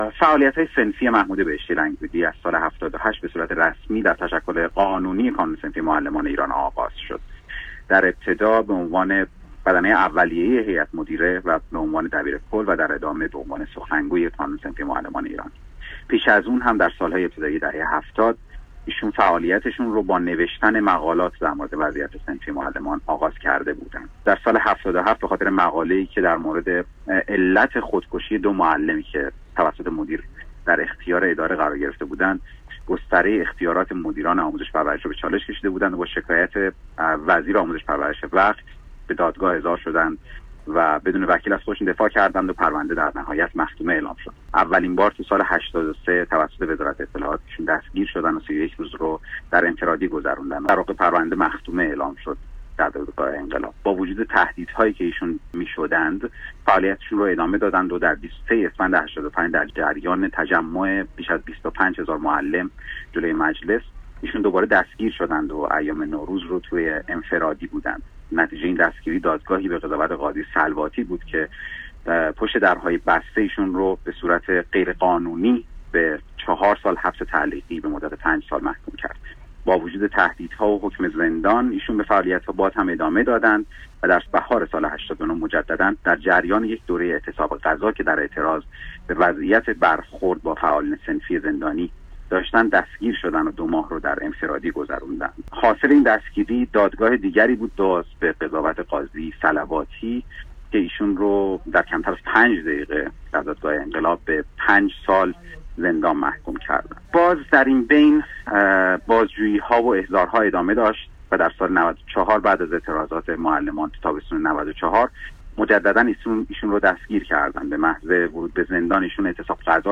گفت‌وگوی صدای آمریکا